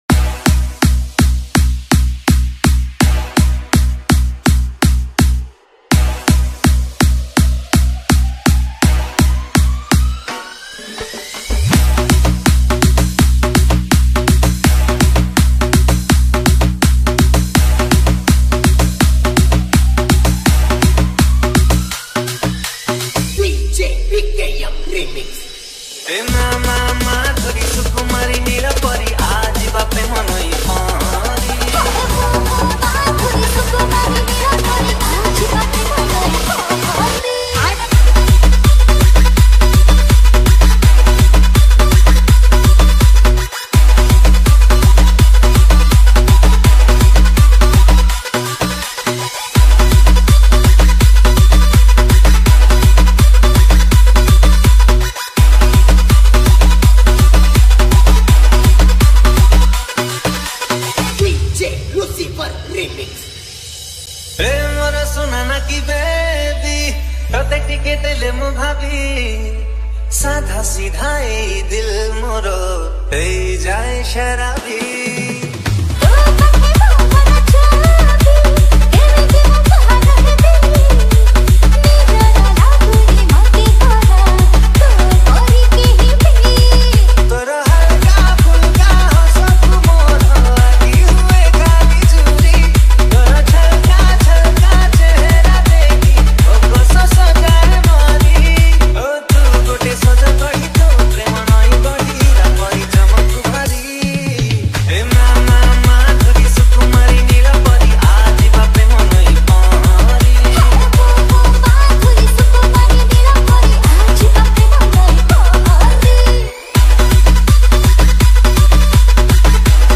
Single Dj Song Collection 2025 Songs Download